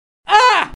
Play CSGO Taser - SoundBoardGuy
Play, download and share CSGO Taser original sound button!!!!
csgo-taser.mp3